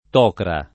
Tocra [ t 0 kra ]